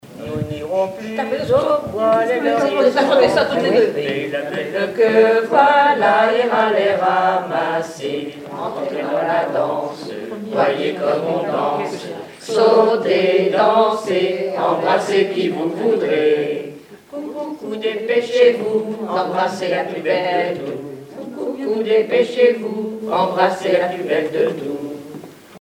ronde à embrasser
Chansons et formulettes enfantines
Pièce musicale inédite